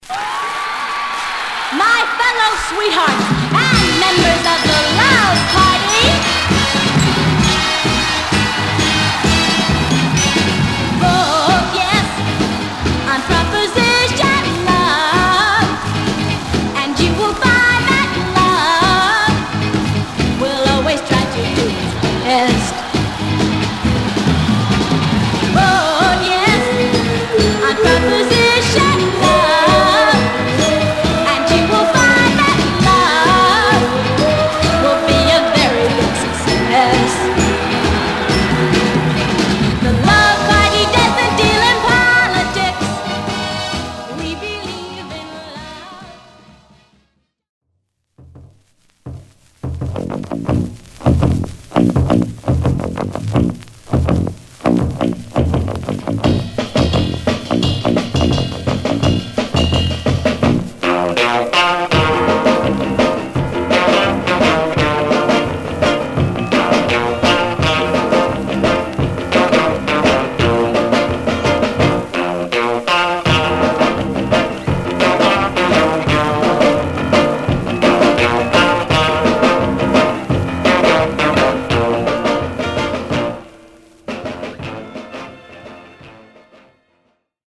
Some surface noise/wear Stereo/mono Mono